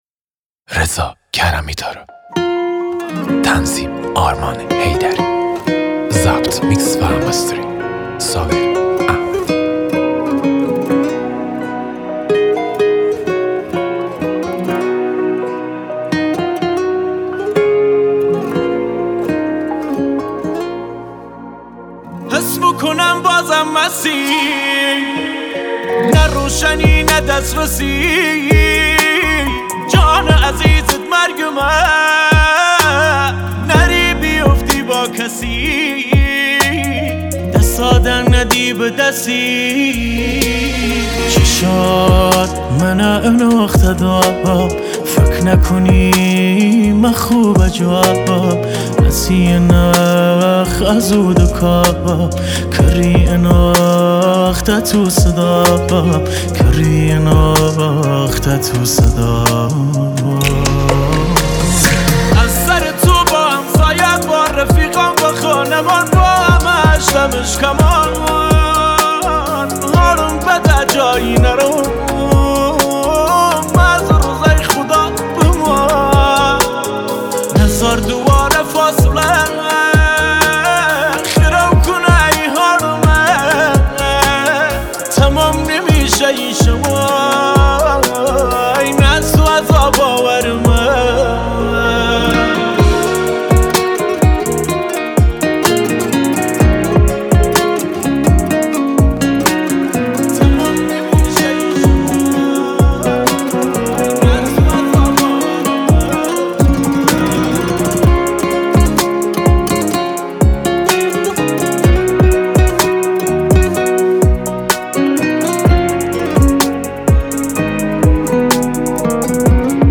آهنگ کردی غمگین آهنگ های پرطرفدار کردی